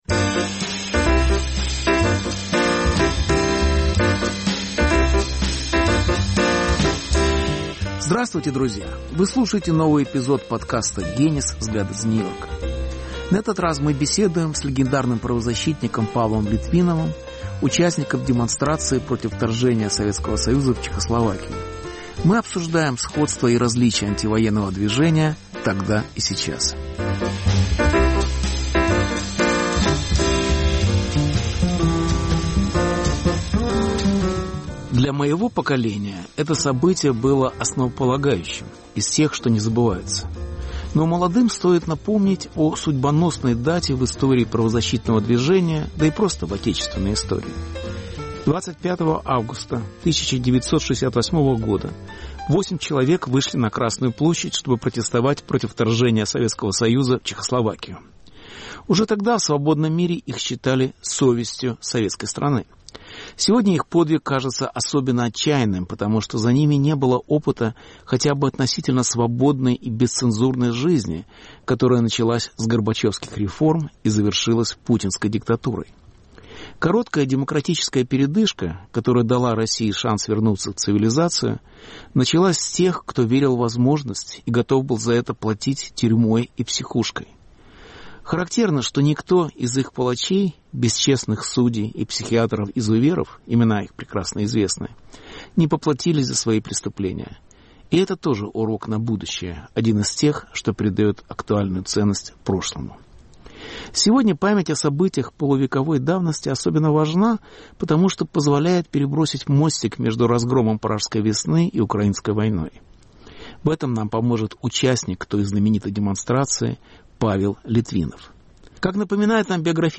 Беседа с правозащитником Павлом Литвиновым, участником демонстрации против разгрома пражской весны 25 августа 1968 года. Повтор эфира от 16 октября 2022 года.